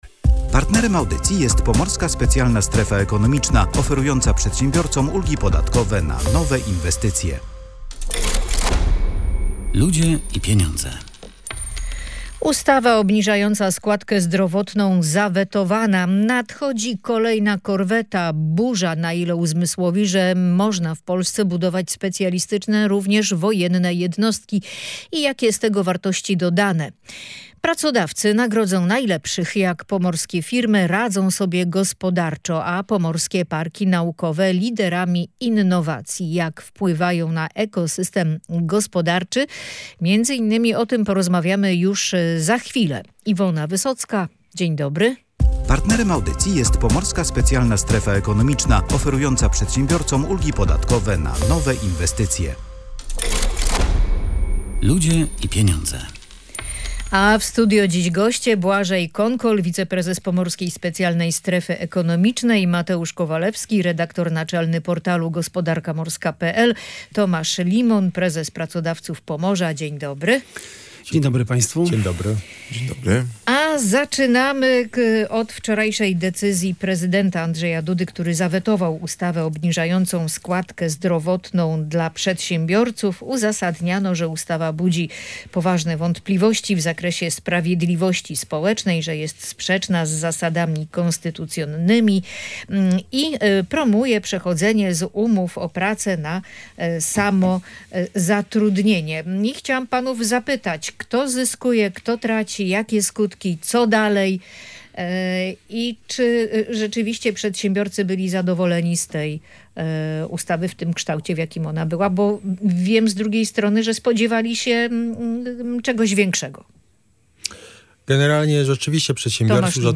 Mamy nadzieję, że prezydenckie weto nie kończy tematu obniżenia składki zdrowotnej dla przedsiębiorców - mówili goście audycji "Ludzie i Pieniądze".